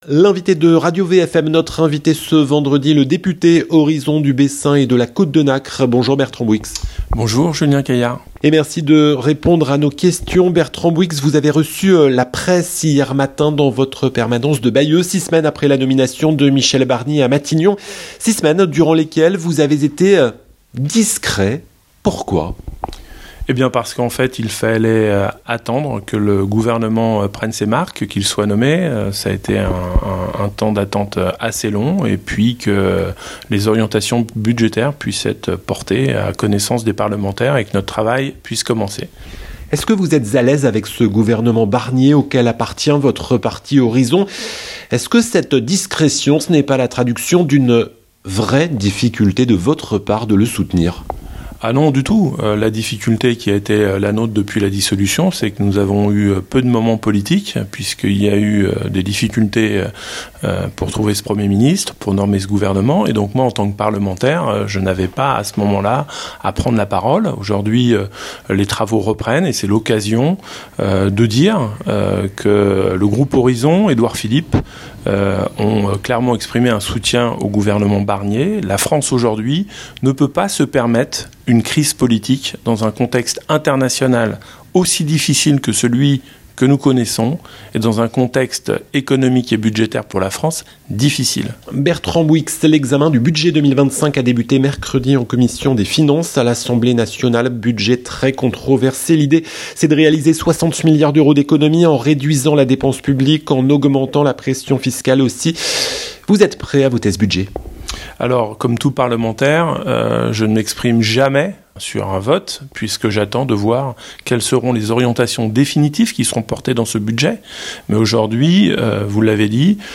Bertrand Bouyx Bertrand Bouyx, le député Horizons de la 5e circonscription du Calvados (Bessin et Côte de Nacre) est l'invité de la rédaction de Radio VFM ce vendredi 18 octobre 2024, à 8 h 20.